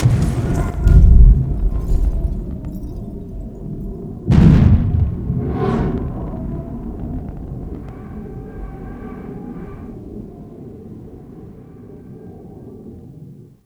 EXPLOSE1  -L.wav